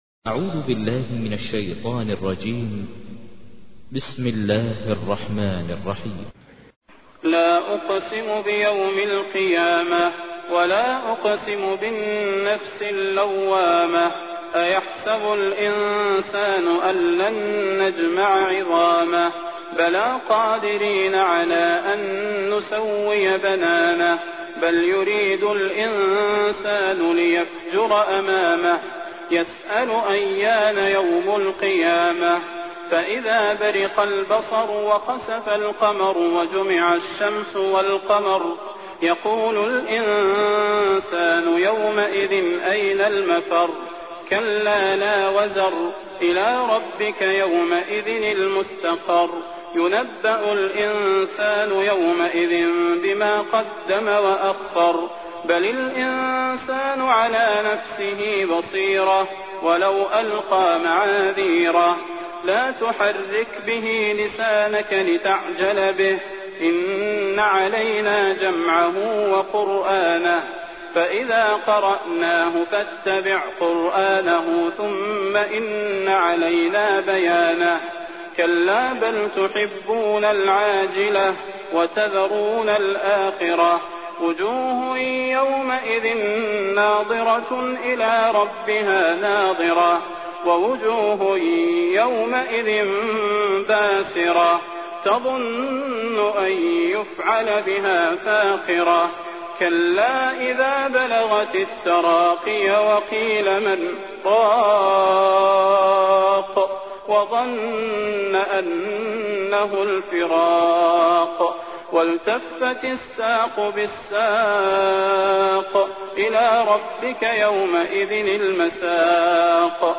تحميل : 75. سورة القيامة / القارئ ماهر المعيقلي / القرآن الكريم / موقع يا حسين